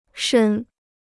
伸 (shēn): to stretch; to extend.